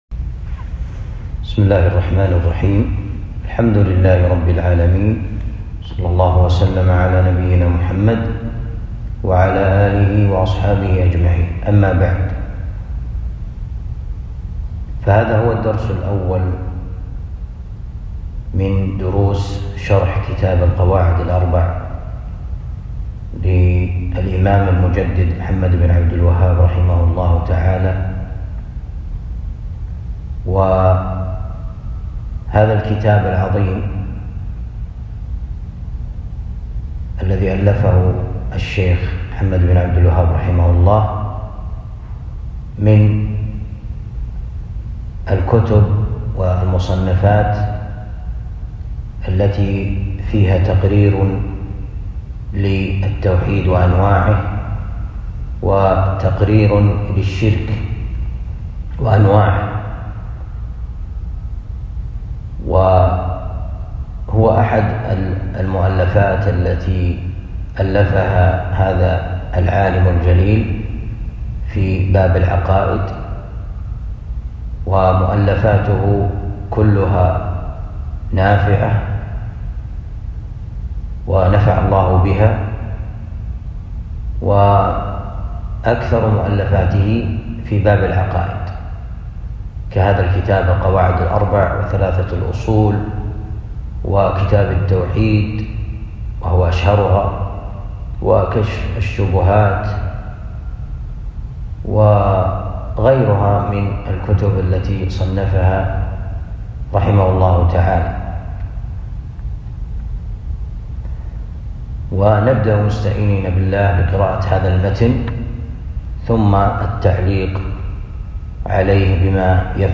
1. الدرس الأول من شرح القواعد الأربع